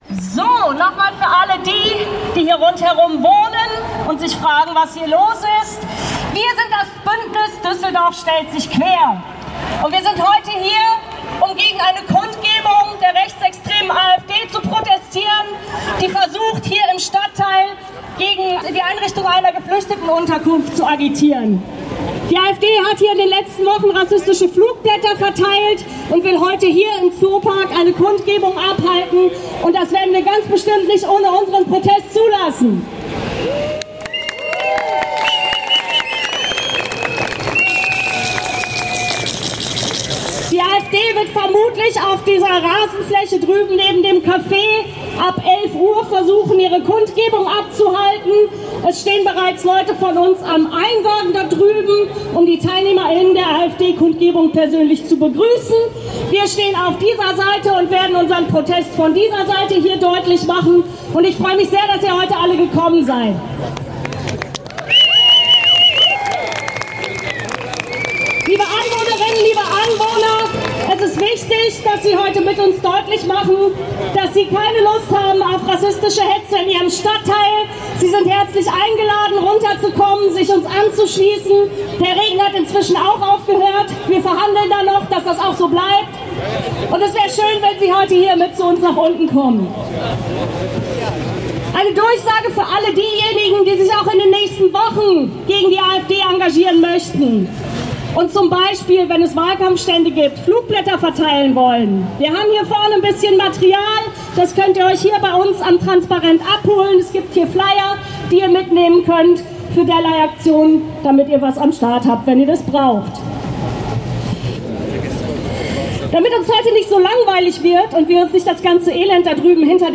Kundgebung „Düsseldorf stellt sich quer gegen extreme Rechte und Rassismus!“ (Audio 1/7)